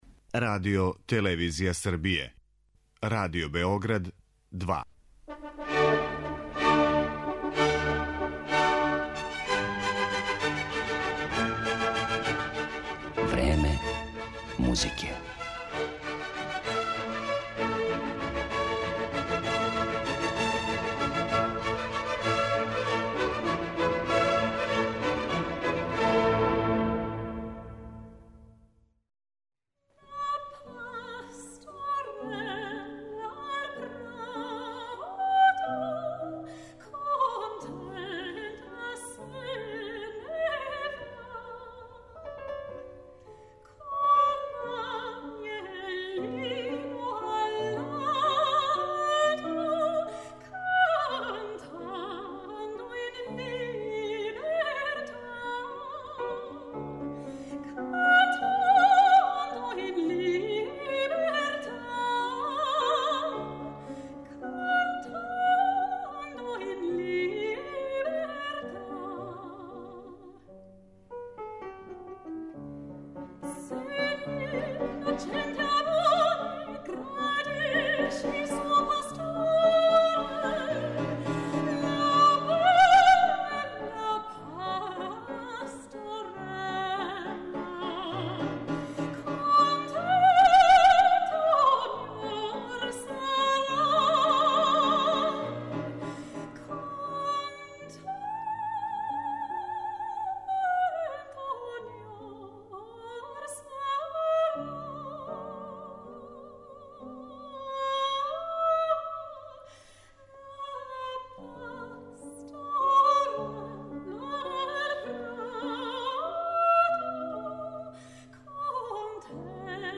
Композиције Августа Хербинга, Карла Филипа Емануела Баха, Фридриха Флајшера, Кристијана Волфа и Волфганга Амадеуса Моцарта изводиће једна од најатрактивнијих вокалних солисткиња данашњице, британски сопран Керолајн Семпсон.